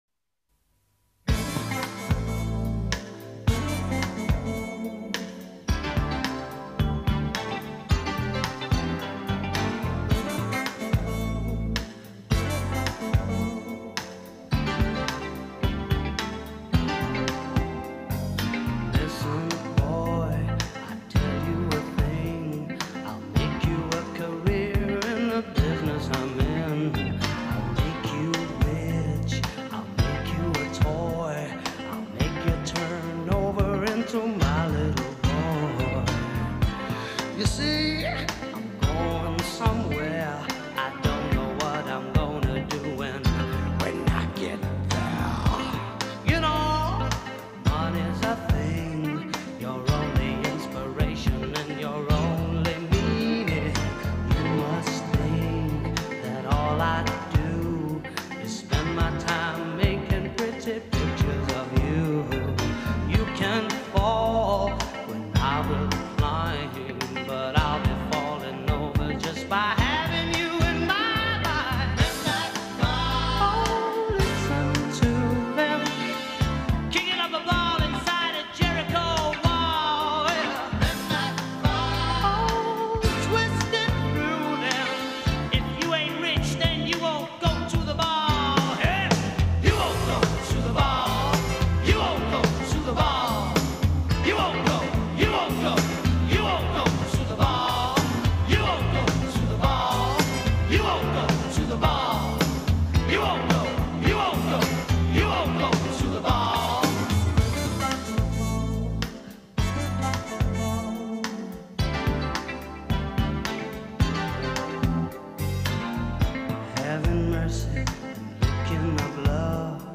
lo han mezclado todo: el folk, el reageen, etc..Soberbio